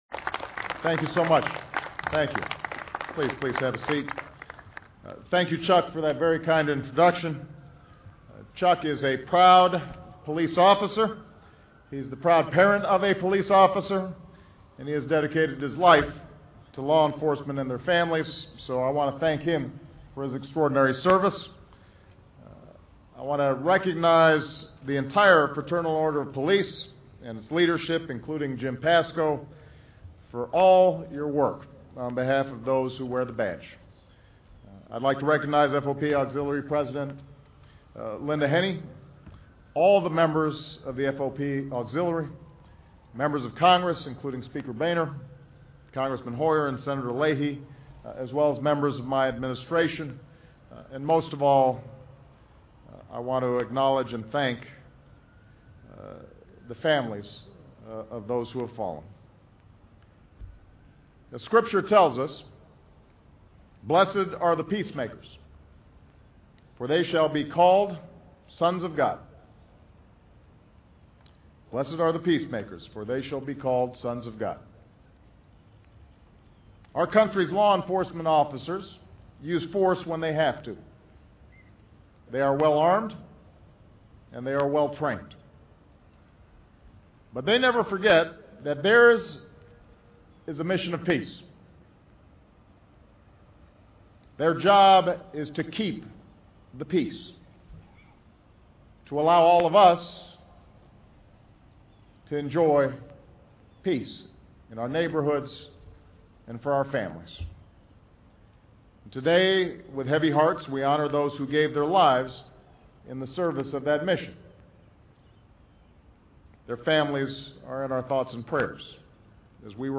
奥巴马总统每周电台演讲:总统表彰警官们的无私奉献精神 听力文件下载—在线英语听力室